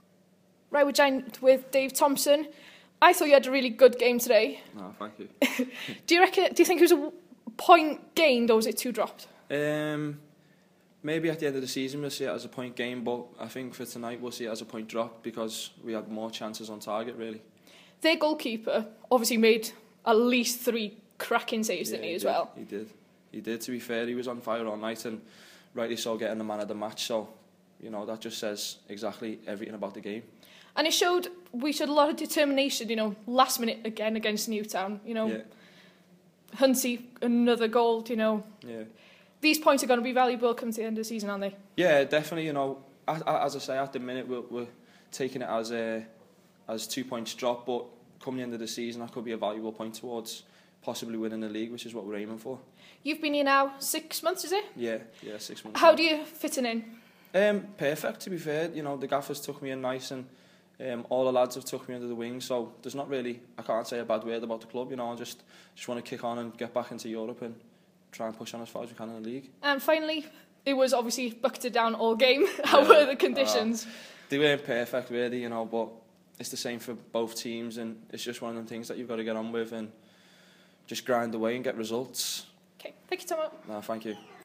Newtown post-match interview